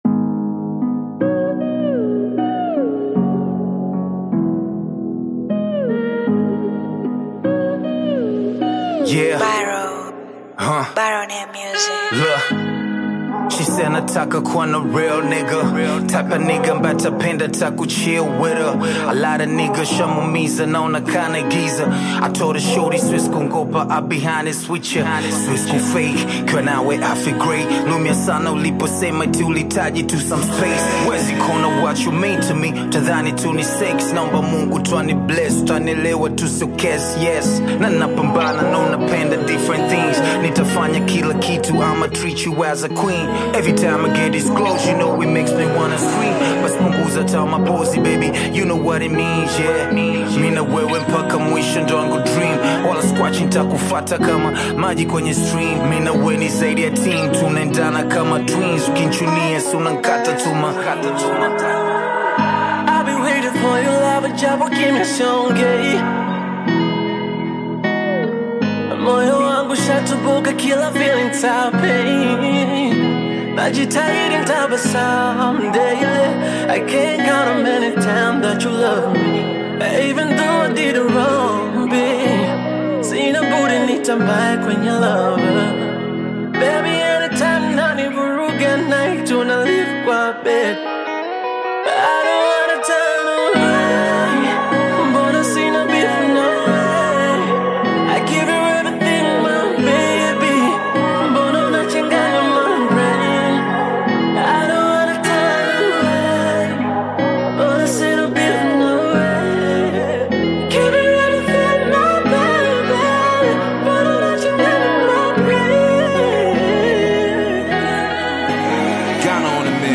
Bongo flava
hip-hop and R&B song
soulful vocals capture the mix of vulnerability and strength